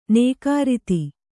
♪ nēkārity